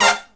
HIGH HIT07-R.wav